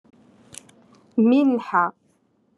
Moroccan Dialect- Rotation Three- Lesson Five